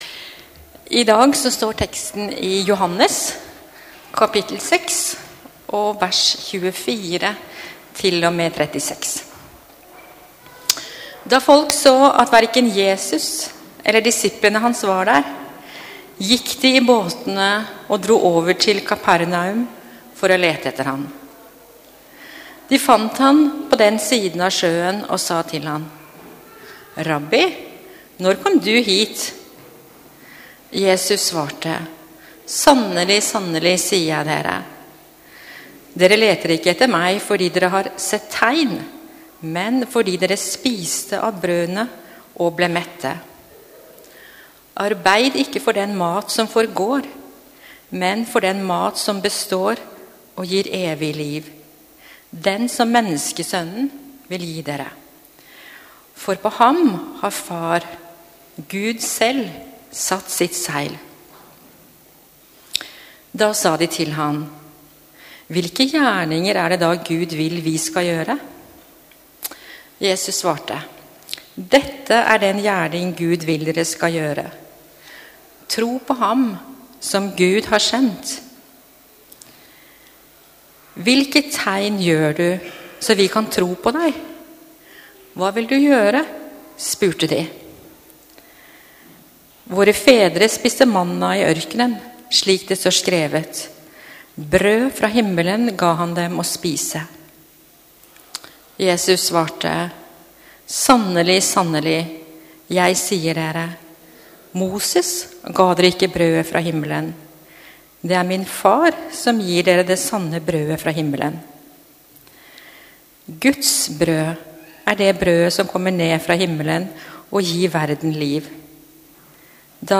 Gudstjeneste 3. april 2022,- Livets brød | Storsalen